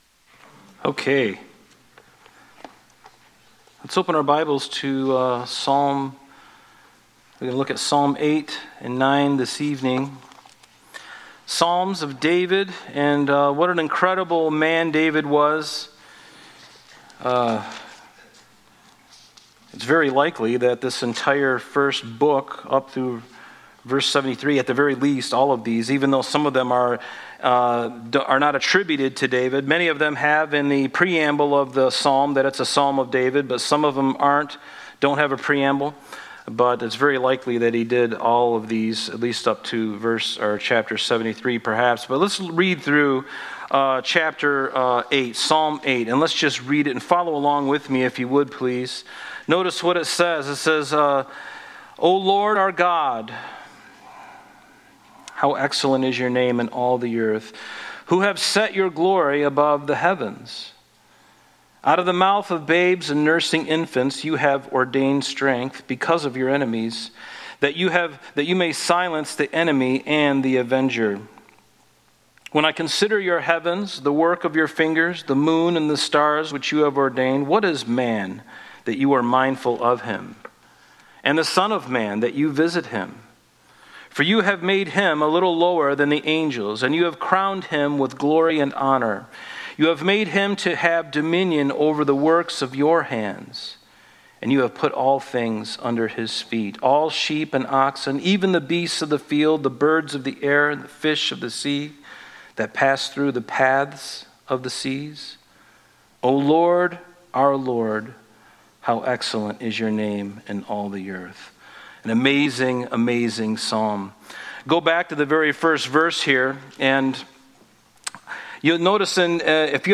Thursday Night Bible Study